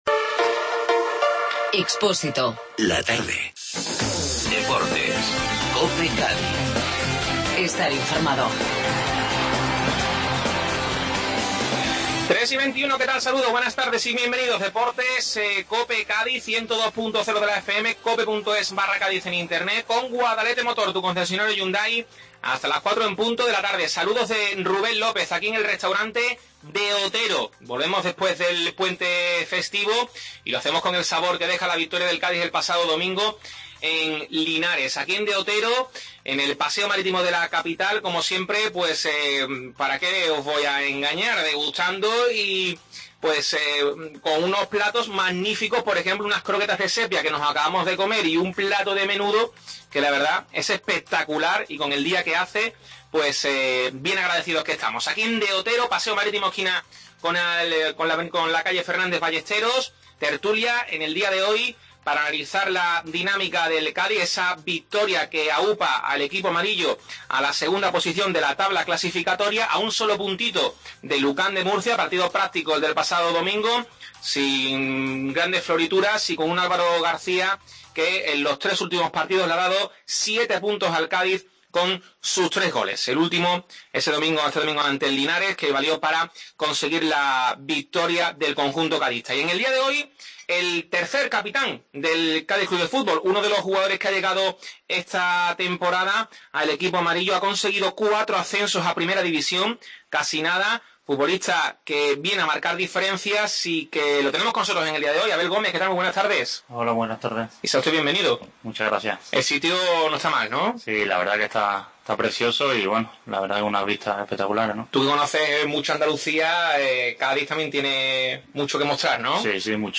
protagonista en la tertulia desde el Restaurante De Otero